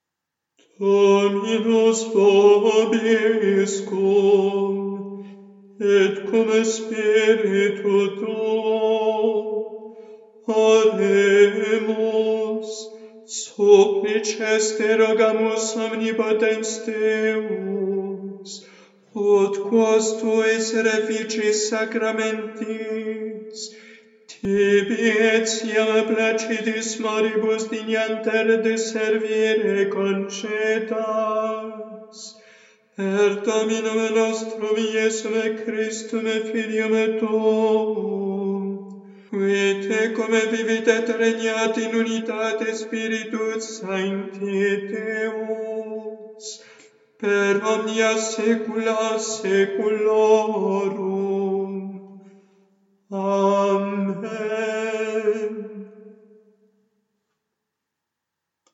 Postcommunion